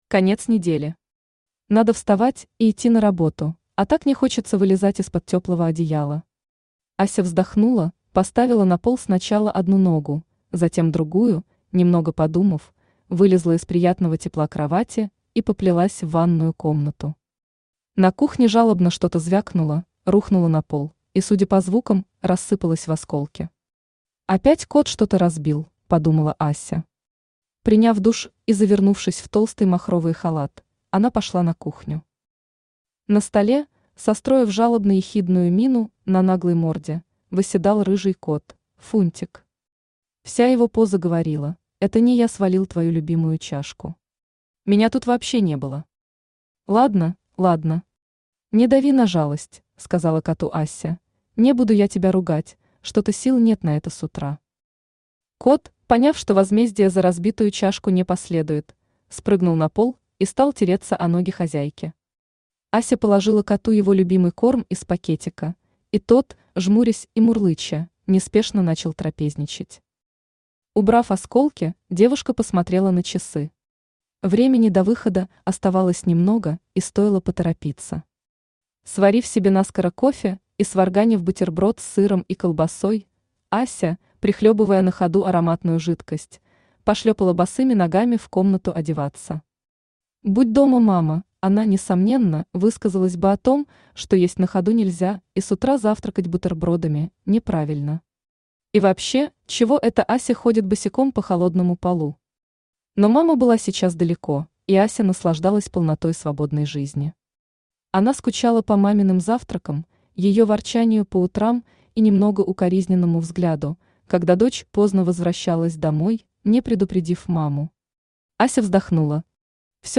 Аудиокнига Особняк с летучими мышами | Библиотека аудиокниг
Aудиокнига Особняк с летучими мышами Автор Наталия Анатольевна Алексеева Читает аудиокнигу Авточтец ЛитРес.